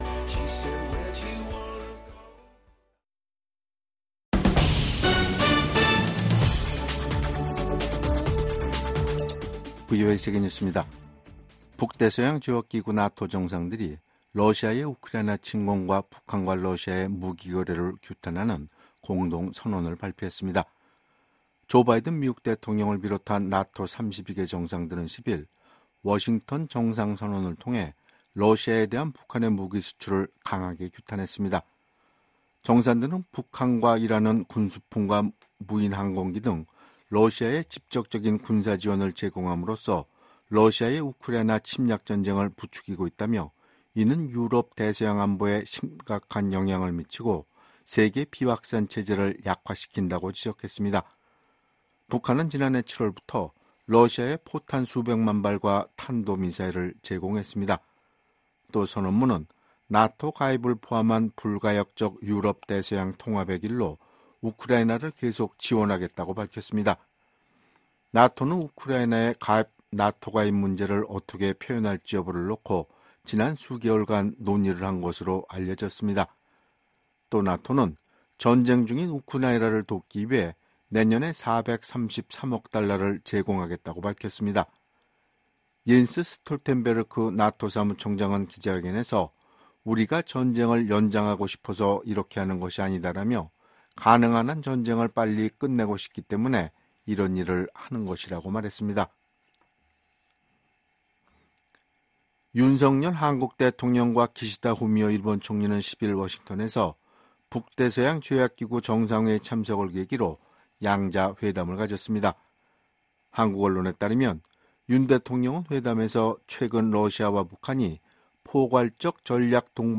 VOA 한국어 간판 뉴스 프로그램 '뉴스 투데이', 2024년 7월 11일 3부 방송입니다. 미국 대통령을 비롯한 북대서양조약기구(NATO∙나토) 정상들이 러시아에 대한 북한의 무기 수출을 규탄하는 공동선언을 발표했습니다. 미국 백악관은 한국과 일본 등 인도태평양 파트너 국가들이 참여하는 나토 정상회의에서 북러 협력 문제를 논의할 것이라고 예고했습니다. 한국과 나토 국가들 간 방산협력 등 연계가 앞으로 더욱 강화될 것이라고 미국 전문가들이 전망했습니다.